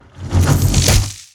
PUNCH_ELECTRIC_HEAVY_05.wav